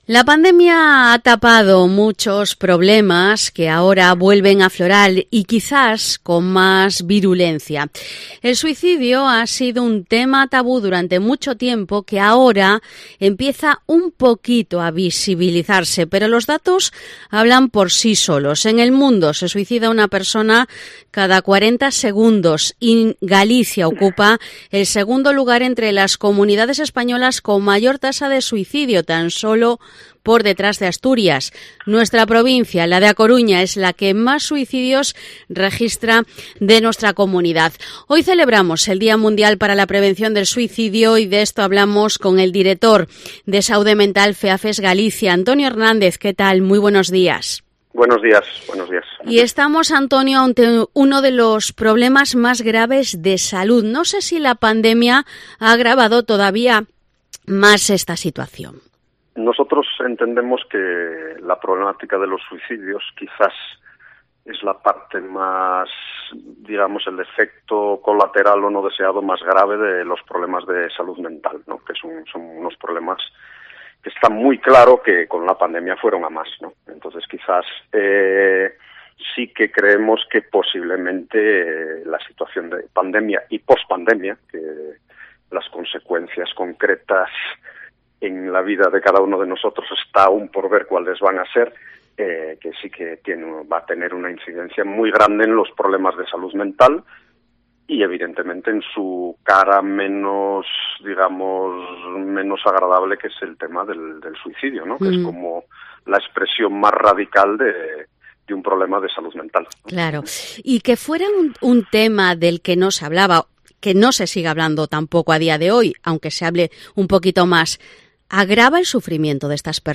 Entrevista FEAFES Galicia